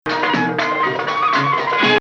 Bayati Taheya 04 Rast.mp3